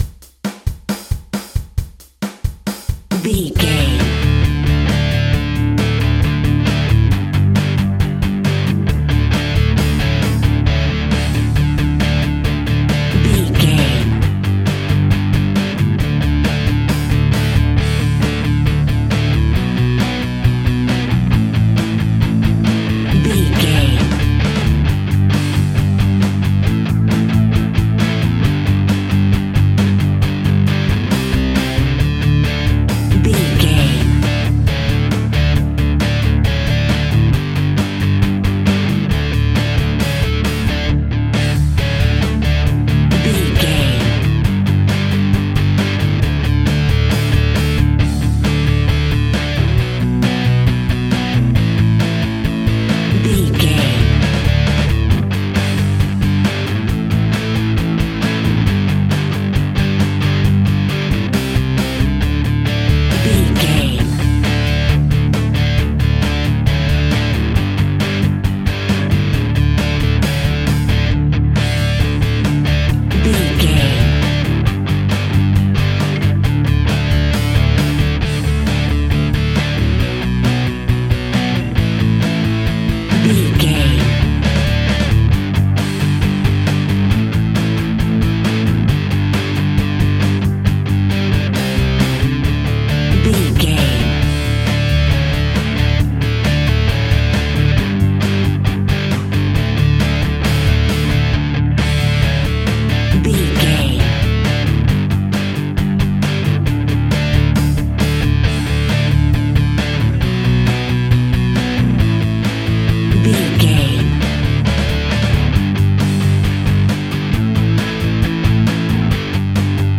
Epic / Action
Fast paced
Ionian/Major
Fast
distortion
punk metal
Instrumental rock
punk rock
drums
bass guitar
electric guitar
piano
hammond organ